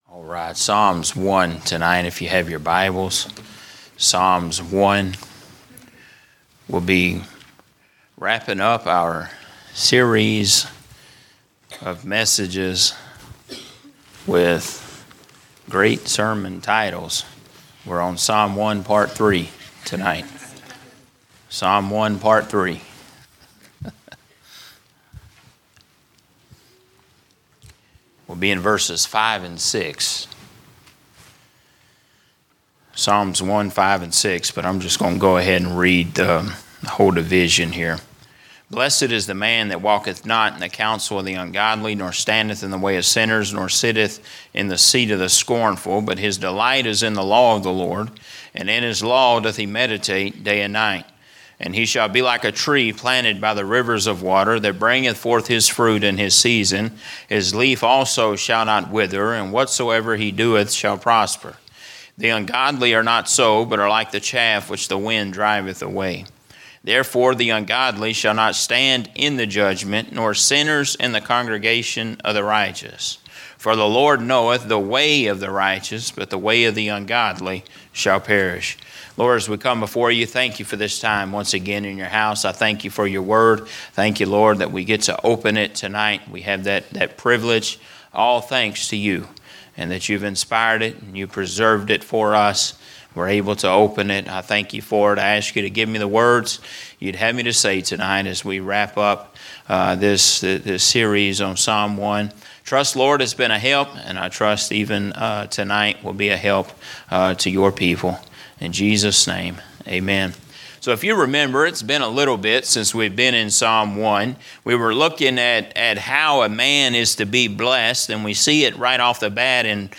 A message from the series "General Preaching." A study of Psalm chapter one